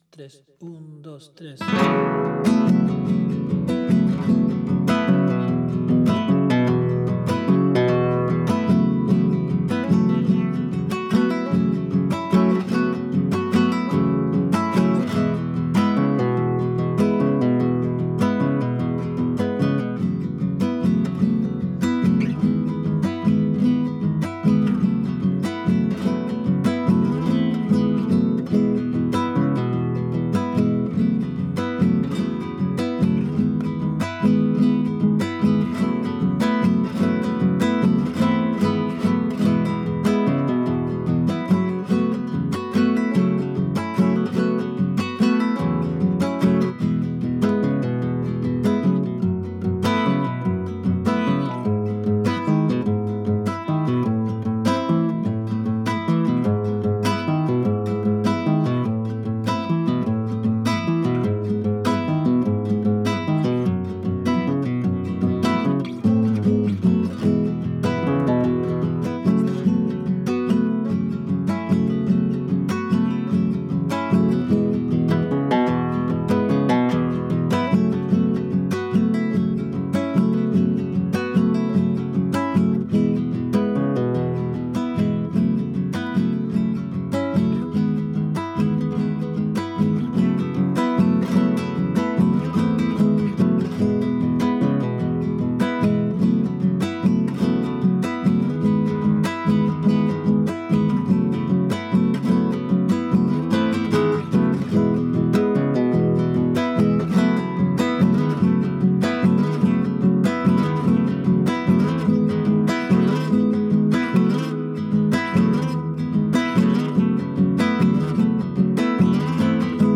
Descargar Guitarra